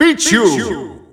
Announcer pronouncing Pichu's name in Dutch.
Pichu_Dutch_Announcer_SSBU.wav